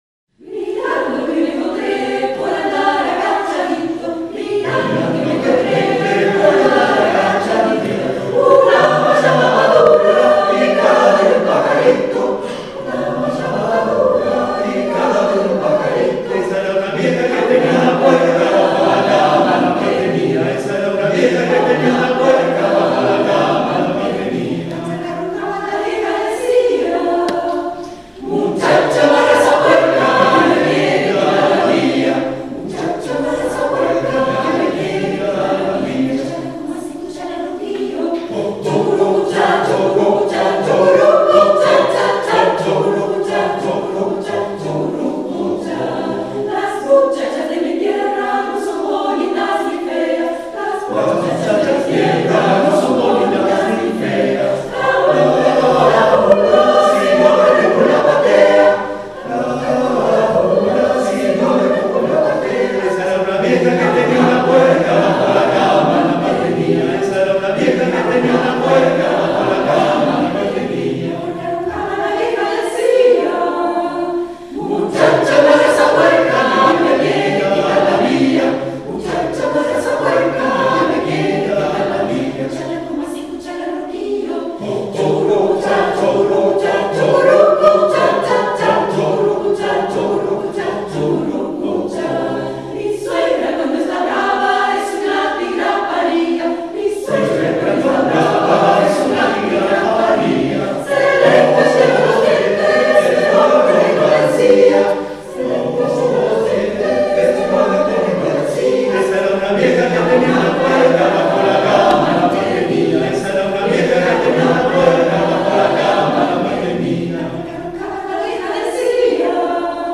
Tradicional Venezolana.